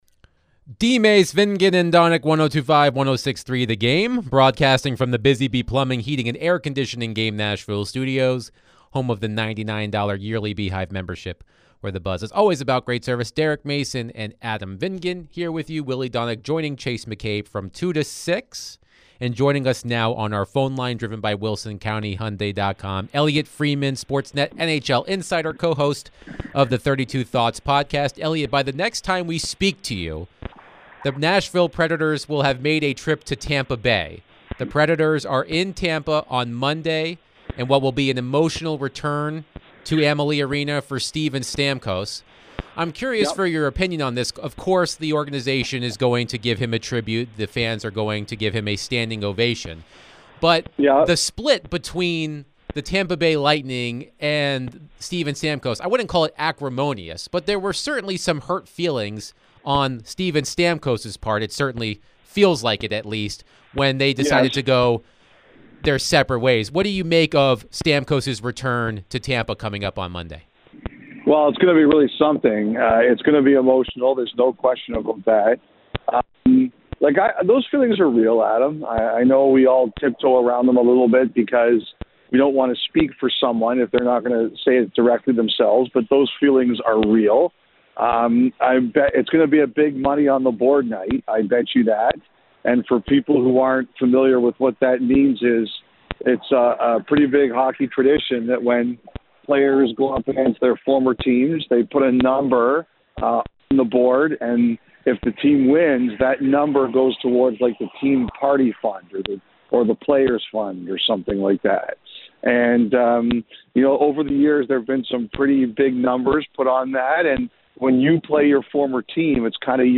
NHL Insider Elliotte Friedman joined the show to share his thoughts on the Predators' season so far. Could the Preds make a move to acquire a new center?